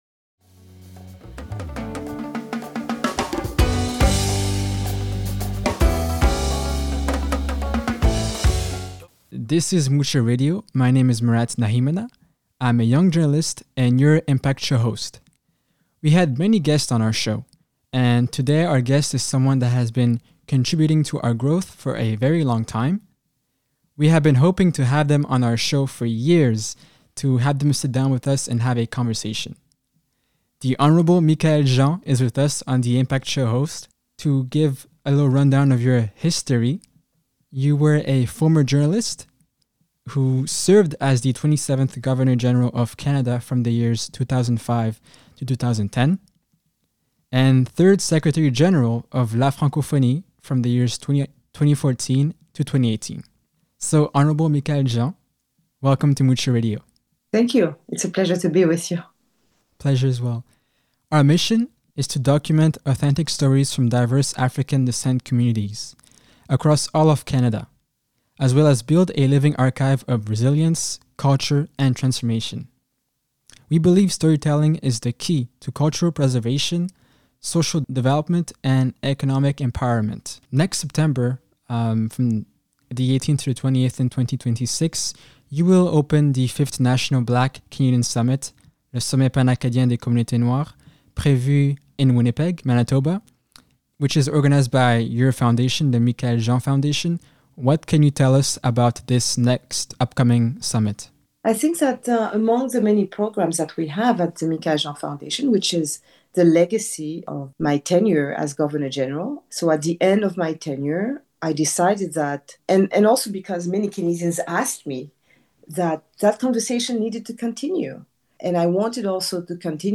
Long format interview